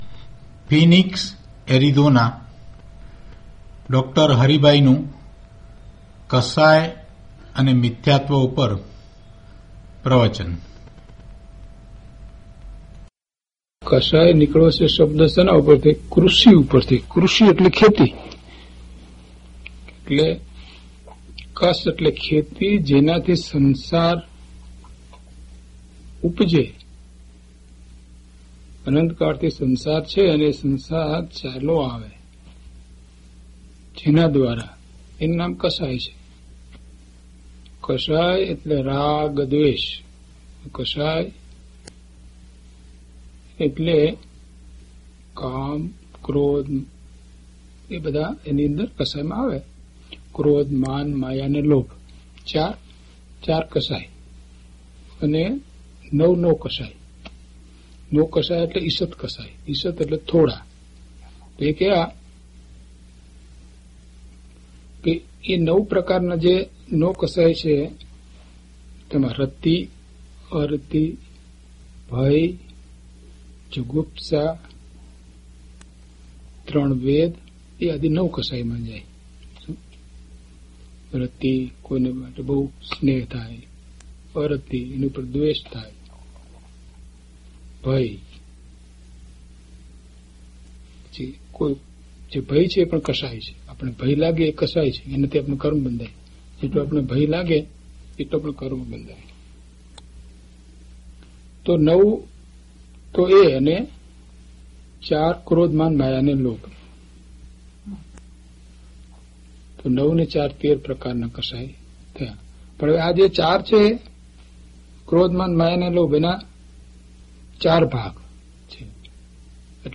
DHP044 Kashay ane Mithyatva - Pravachan.mp3